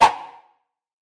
На этой странице собраны звуки катаны и других японских мечей в высоком качестве.
Звук взмаха катаны со свистом